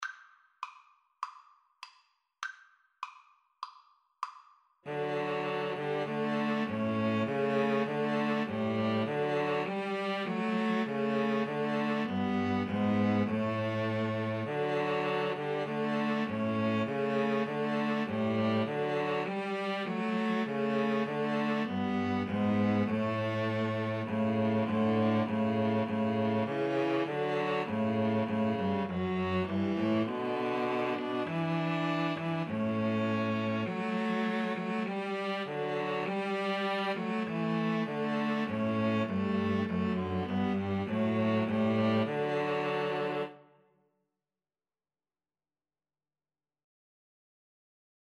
4/4 (View more 4/4 Music)
Classical (View more Classical String trio Music)